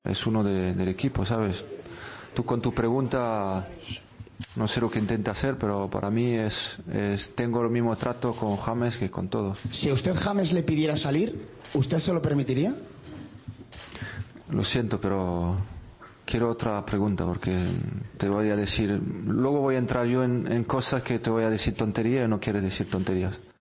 AUDIO: Escucha el enfado de Zidane a las preguntas sobre James